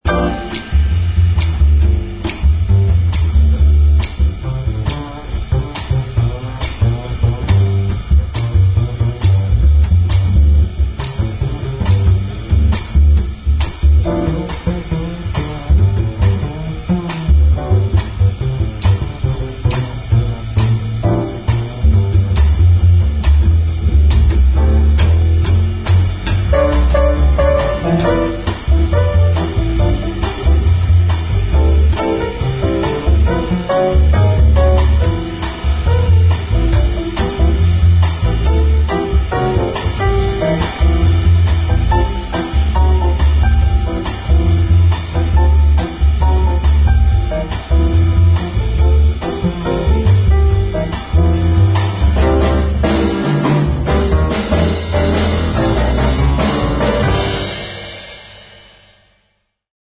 The entire session took just over 2 hours.
a groovy spiritual